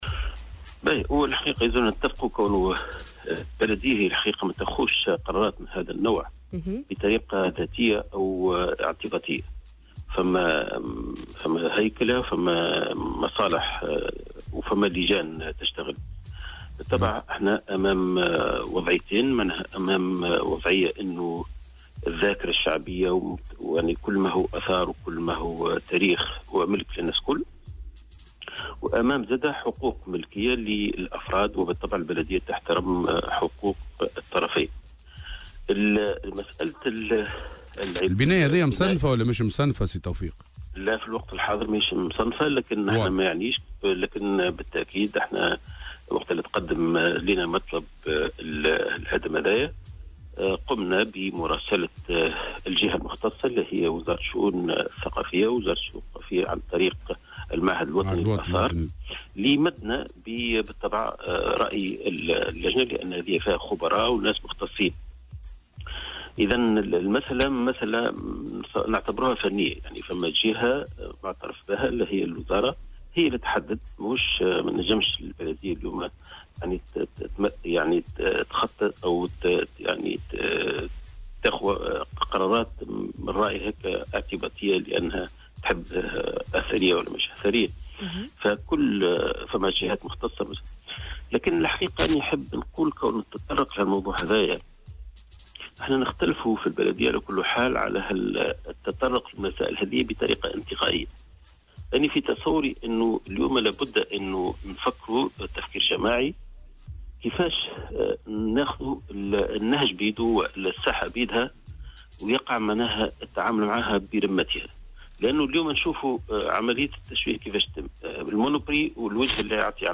وأوضح في مداخلة له اليوم في برنامج "صباح الورد" على "الجوهرة أف ام" أن البناية على ملك خواص وقد تقدّم أصحابها إلى المصالح المعنية بمطلب في الغرض، مشيرا إلى أن البلدية قامت بمراسلة الجهة المعنية وهي وزارة الشؤون الثقافية عن طريق المعهد الوطني للتراث للإدلاء برأيها.